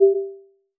Interaction_BasicRay_Release.wav